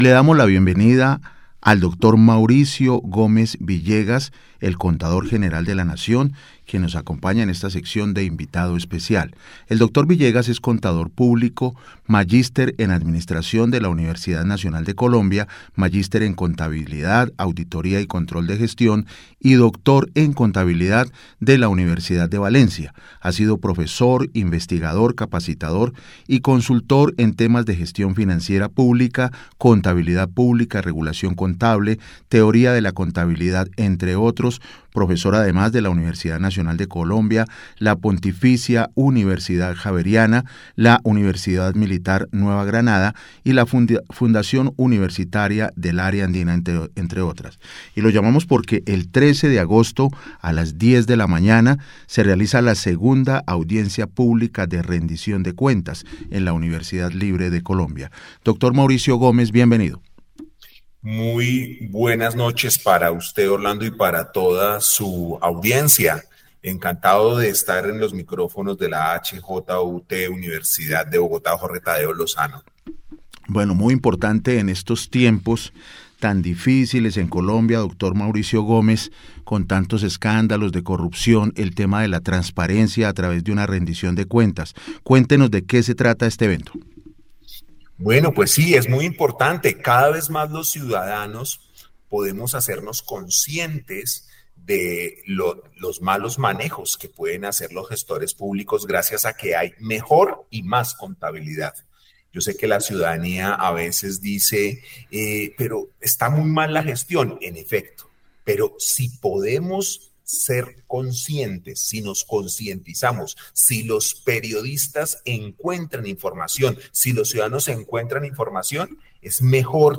entrevista-emisora-hjut